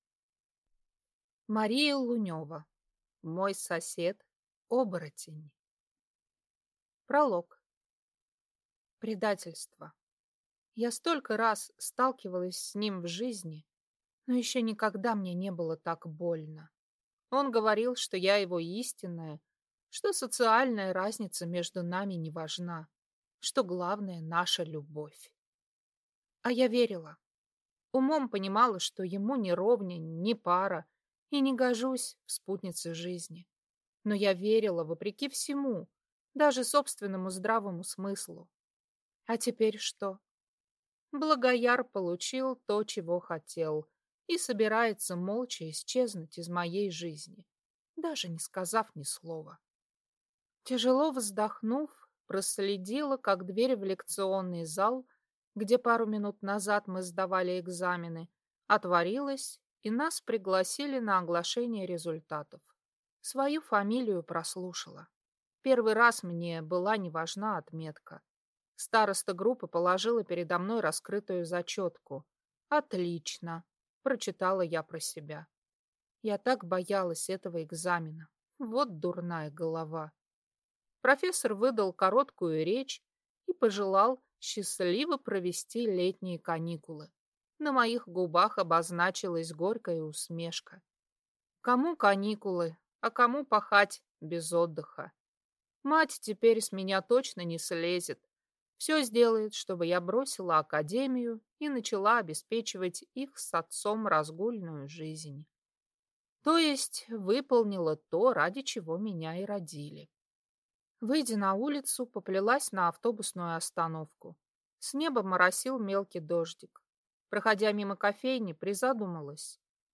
Аудиокнига Мой сосед – оборотень | Библиотека аудиокниг
Прослушать и бесплатно скачать фрагмент аудиокниги